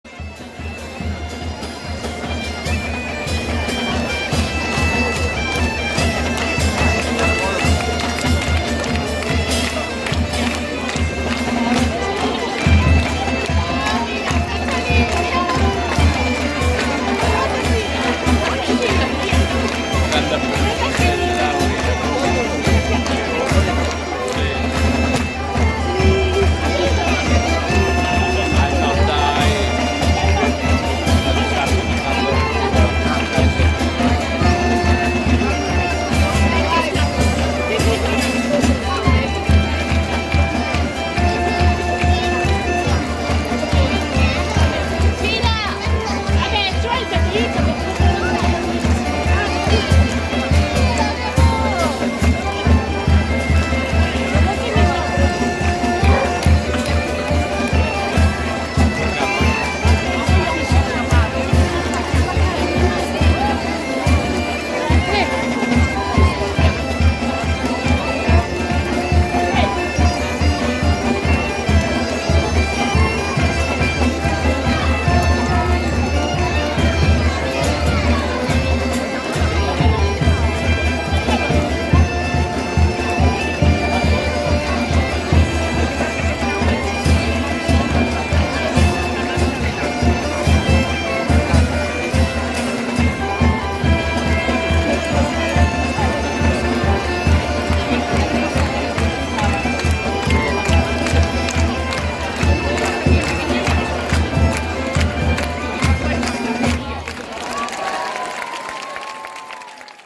El pasodoble es la forma más frecuente porque su ritmo binario continuo facilita el desfile, pero no faltan ritmos de marcha procesional con chirimías como los de Santiago y Lugo.